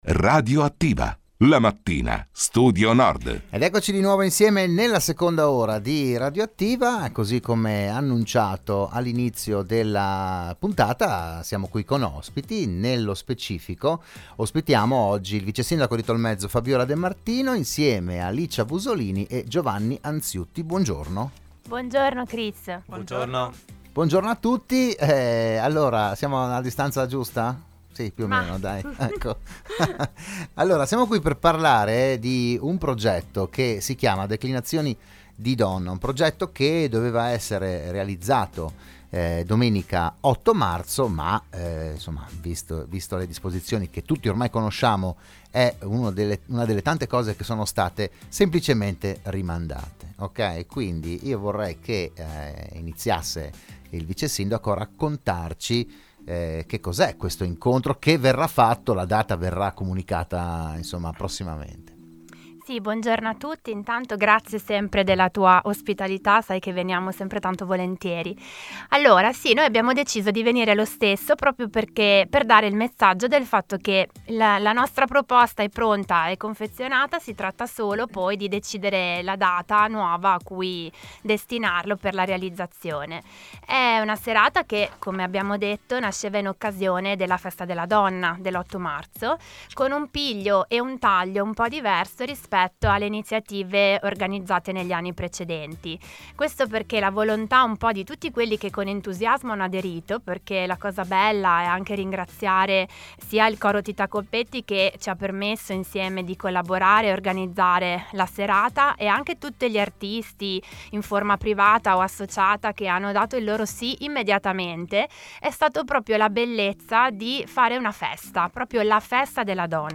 Ospiti a "RadioAttiva" di Radio Studio Nord l'assessore comunale Fabiola De Martino